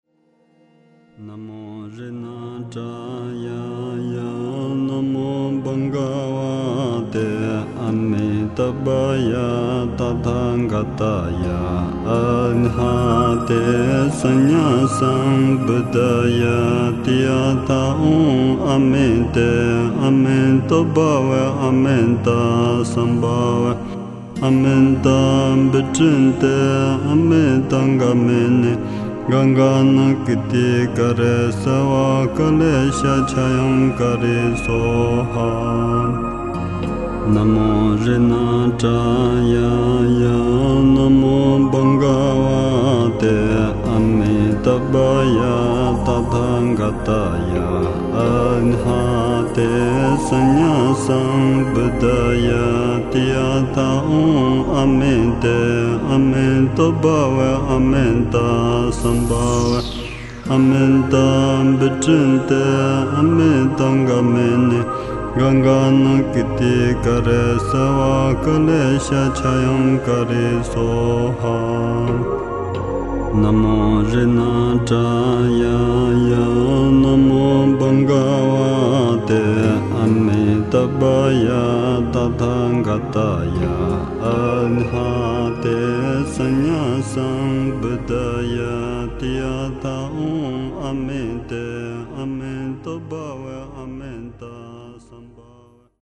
佛曲音樂 > 真言咒語 > 淨土陀羅尼咒